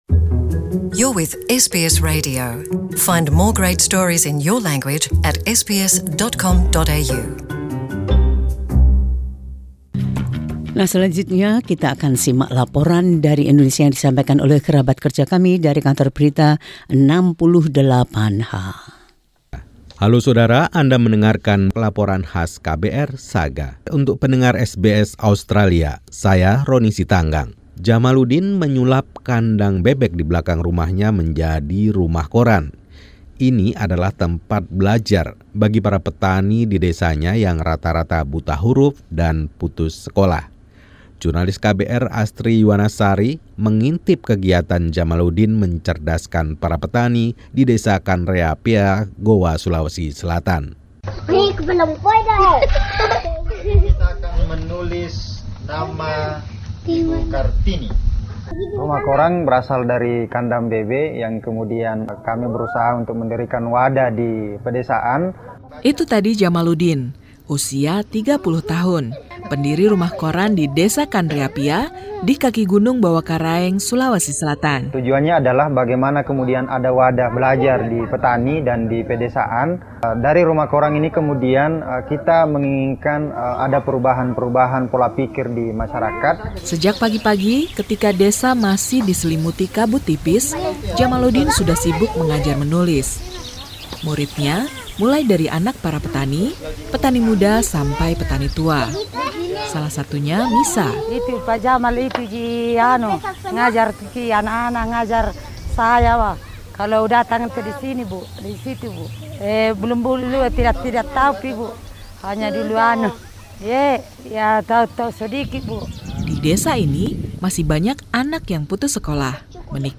Tim KBR 68H melaporkan.